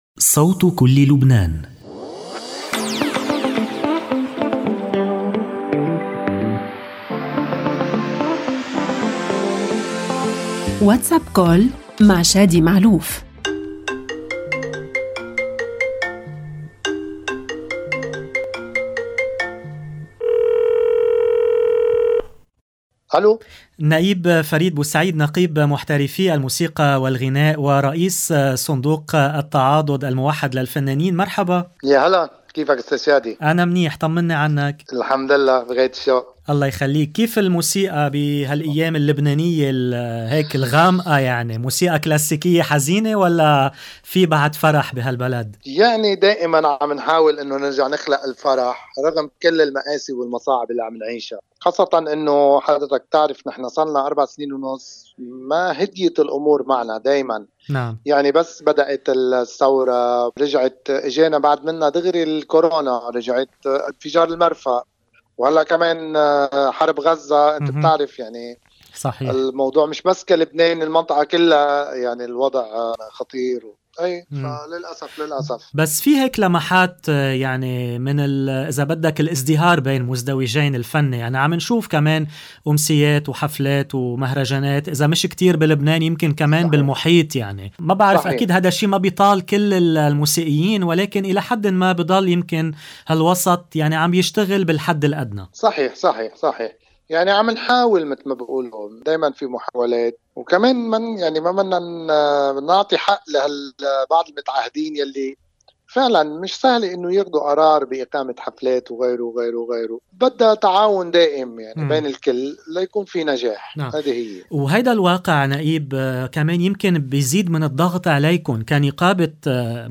WhatsApp Call